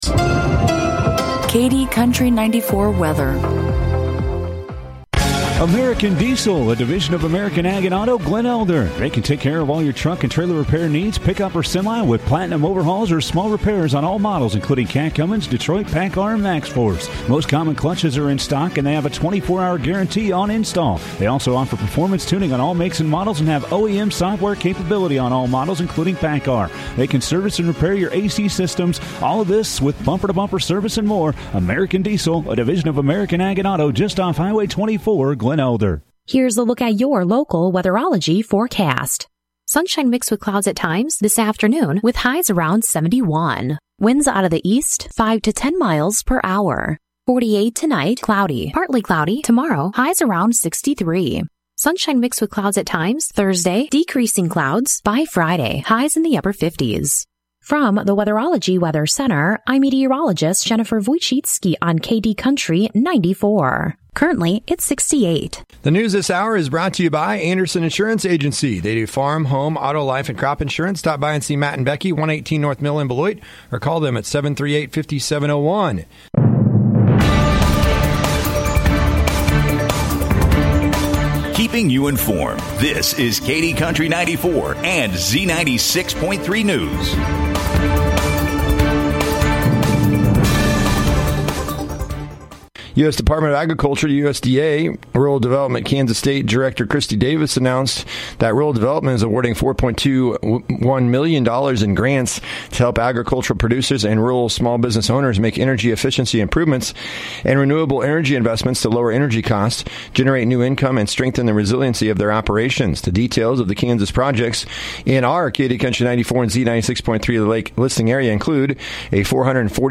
KD Country 94 Local News, Weather & Sports – 11/7/2023